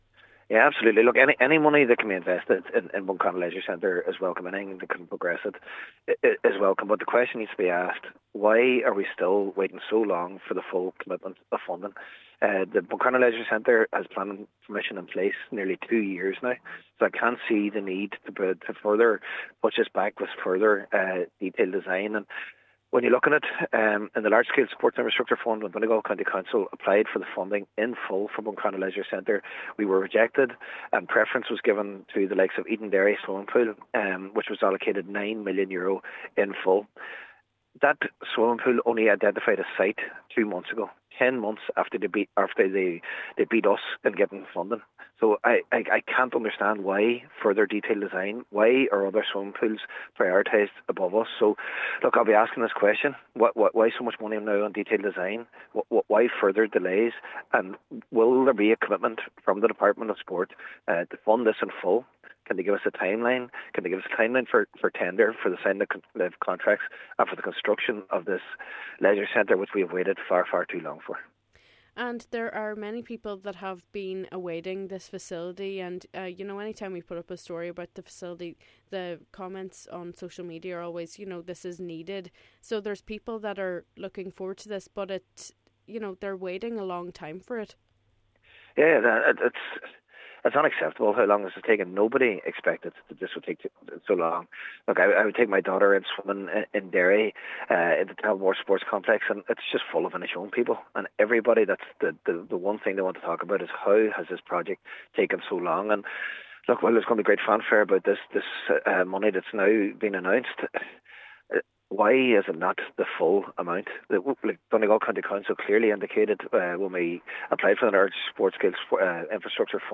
Cllr Murray says what is needed is a clear commitment to the full €17 million required to build the Leisure Centre: